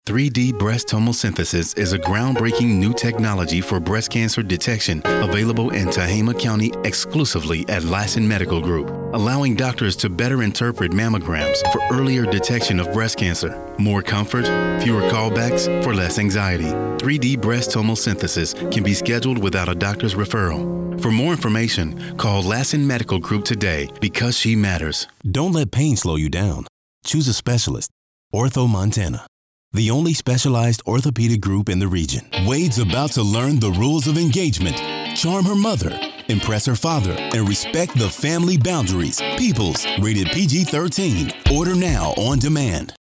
Smooth Bassy Voice Very articulate, Very Clear!
Sprechprobe: eLearning (Muttersprache):